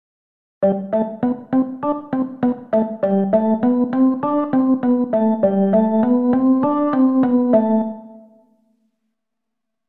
Unter Artikulation versteht man Zusatzangaben, die sich auf die Tondauer beziehen, etwa Staccato (kurz), Portato (breit) und Legato (ohne Lücken aneinander gebunden):
Artikulation - Songwriting Grundlagen
songwriting_06_artikulation.mp3